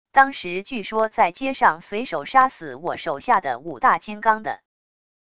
当时据说在街上随手杀死我手下的五大金刚的wav音频生成系统WAV Audio Player